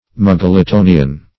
Search Result for " muggletonian" : The Collaborative International Dictionary of English v.0.48: Muggletonian \Mug`gle*to"ni*an\, n. (Eccl. Hist.) One of an extinct sect, named after Ludovic Muggleton, an English journeyman tailor, who (about 1657) claimed to be inspired.